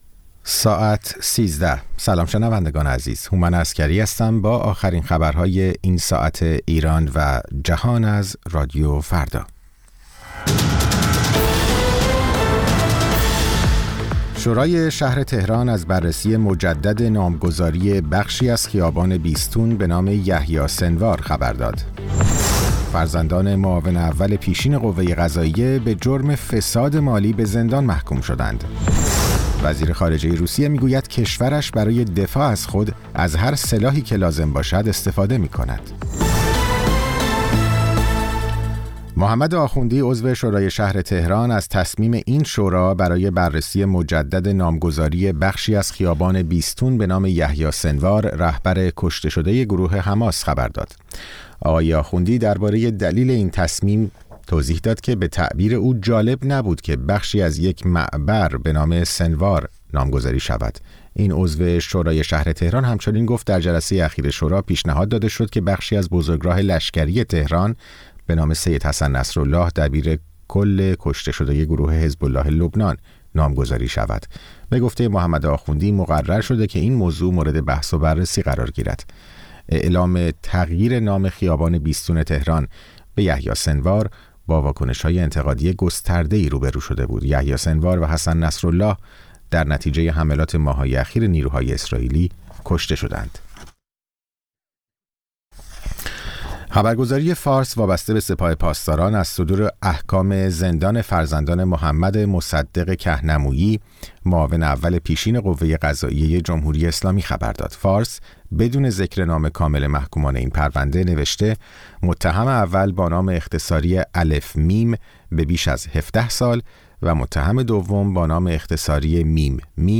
سرخط خبرها ۱۳:۰۰